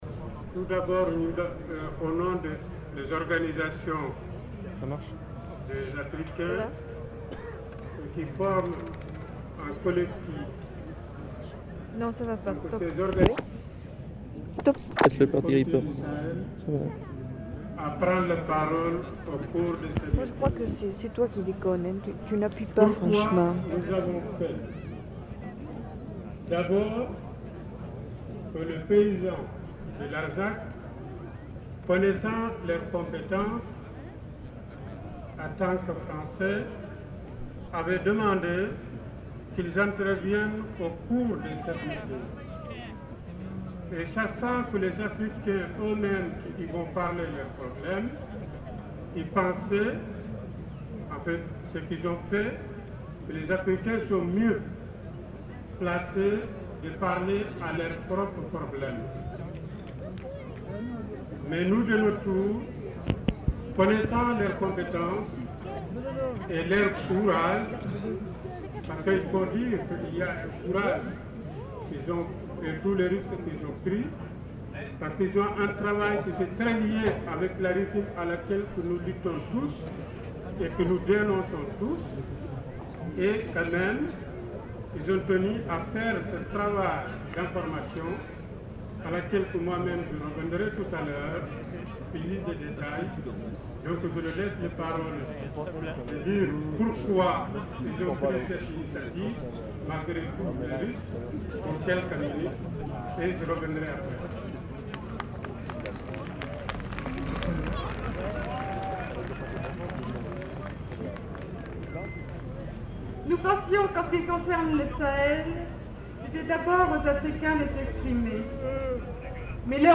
Numéro d'inventaire : 785-13 Plage CD/Page recueil : 4 Durée/Pagination : 21min 33s Dép : 12 Lieu : [sans lieu] ; Aveyron Date : 1974 Genre : parole Notes consultables : Les allocuteurs ne sont pas identifiés. Ecouter-voir : archives sonores en ligne Contenu dans [enquêtes sonores] Larzac 1974